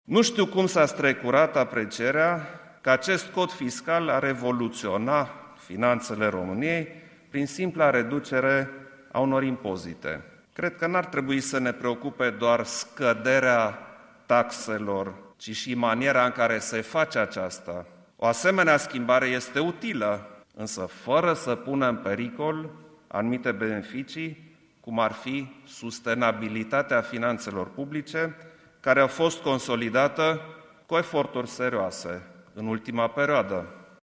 Într-o declaraţie făcută azi la BNR, preşedintele a arătat că România nu îşi mai poate permite experimente economice sau politici publice cu jumătate de măsură, mai ales când acestea atentează la libertatea şi prosperitatea pe termen lung.
Klaus Iohannis a precizat că este nevoie de o nouă abordare bazată pe responsabilitate şi principii sănătoase: